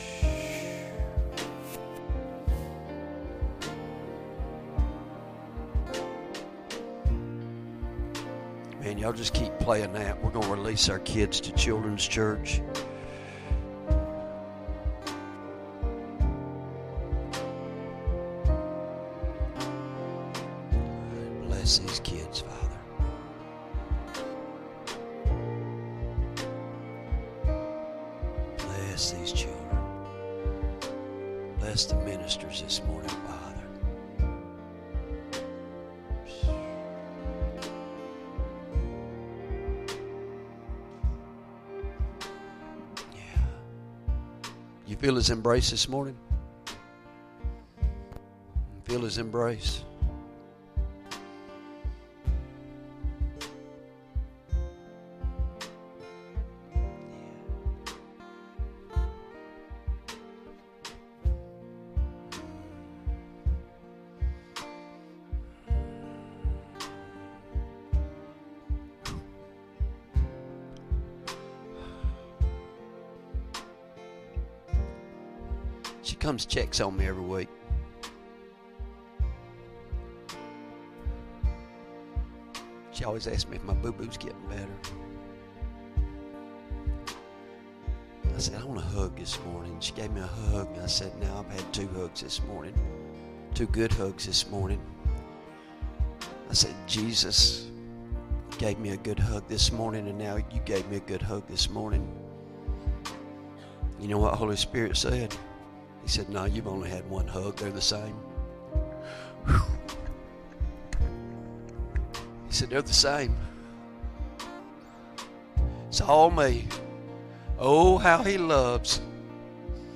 Sermon Audio Downloads | Victory Fellowship